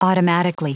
Prononciation du mot automatically en anglais (fichier audio)
Prononciation du mot : automatically